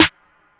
2. CAROUSEL SNARE.wav